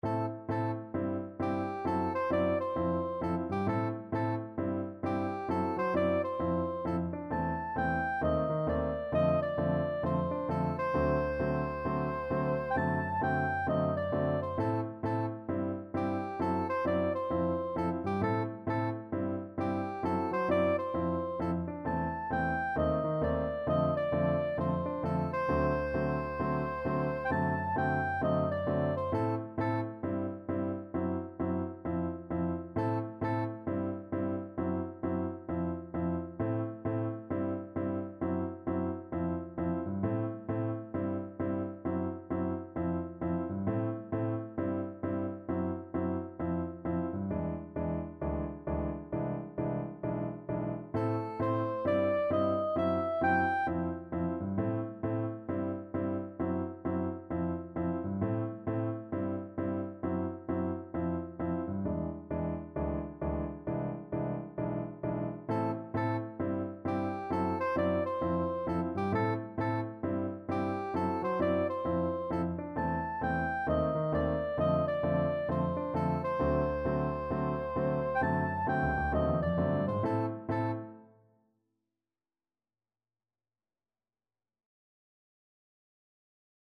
4/4 (View more 4/4 Music)
=132 Swung
Jazz (View more Jazz Soprano Saxophone Music)